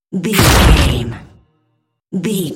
Dramatic hit bloody
Sound Effects
heavy
intense
dark
aggressive
hits